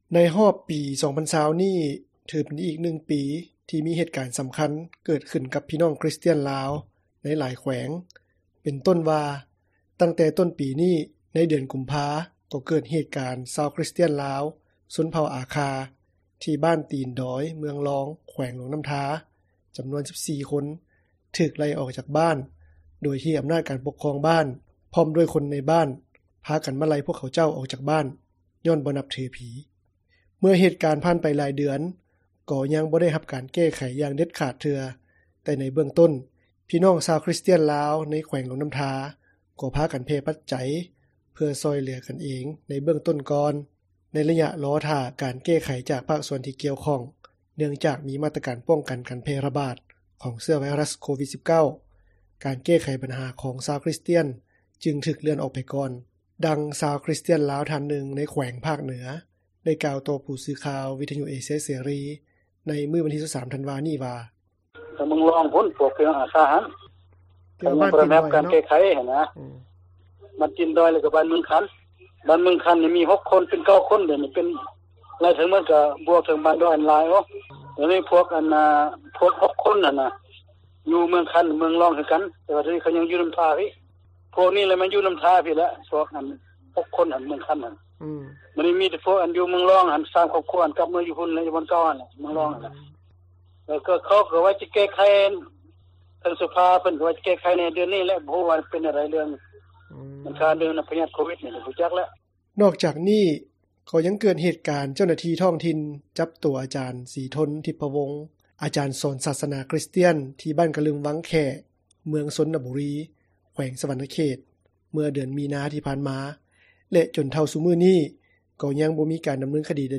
ສຽງ 1: (ຊາວຄຣິສຕຽນລາວ ໃນແຂວງພາກເໜືອ)
ສຽງ 3: (ຊາວຄຣິສຕຽນລາວ ໃນແຂວງພາກໃຕ້)